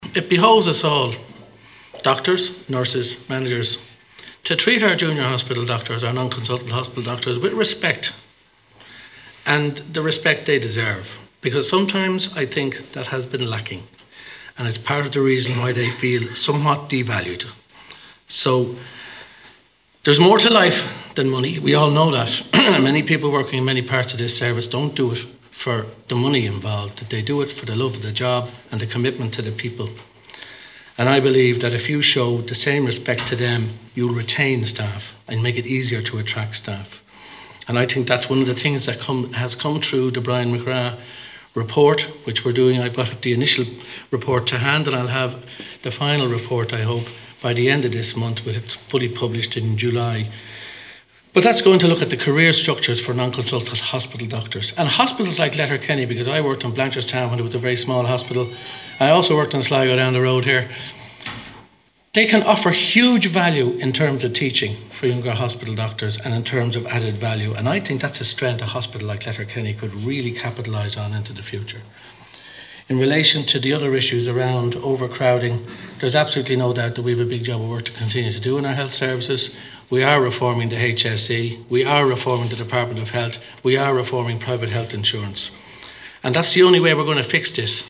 Dr Reilly was speaking in Letterkenny this morning as he re-opened the Emergency Department and Acute Medical Assessment Unit at Letterkenny General.
Dr James Reilly said that part of the recruitment problem relates to how Junior Doctors are treated – he suggested filling vacant posts would be easier if there was a more respectful environment: